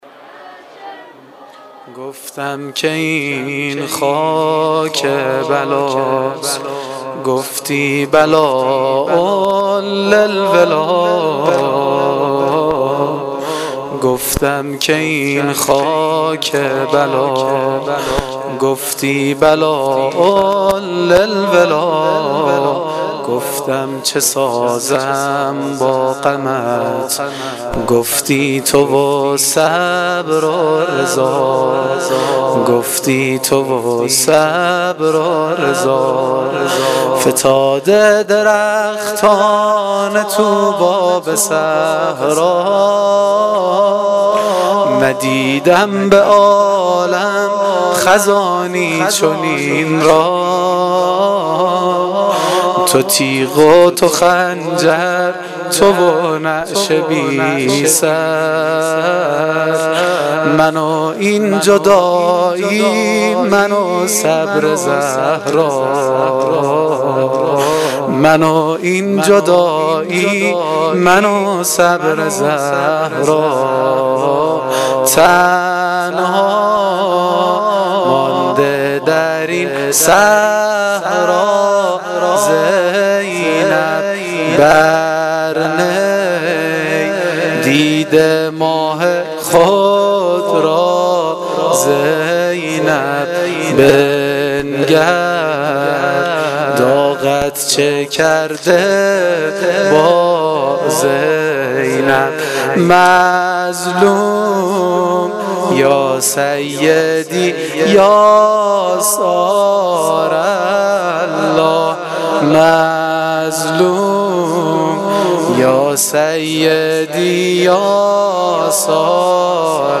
واحد سنگین شب پنجم